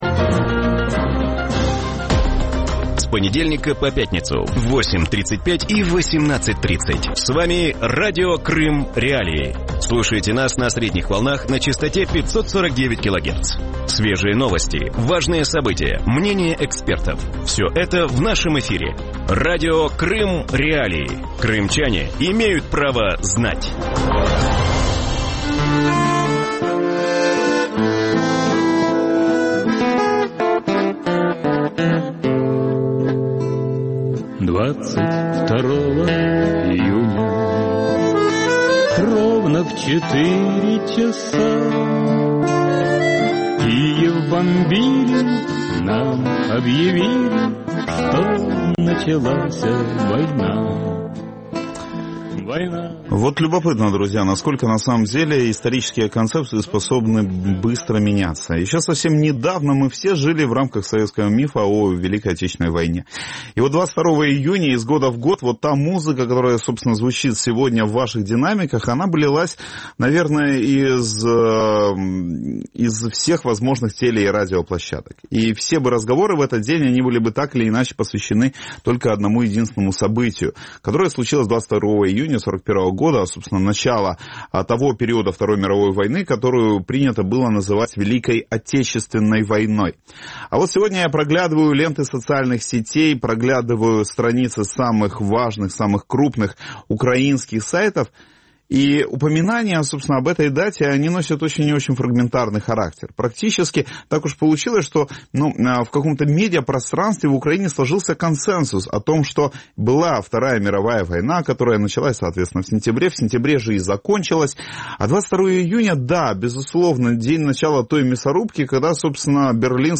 В вечернем эфире Радио Крым.Реалии обсуждают годовщину нападения Германии на Советский союз в 1941 году. Как СССР превратился из страны-агрессора в жертву и почему Россия до сих пор держится за концепцию Великой Отечественной, а Украина – нет?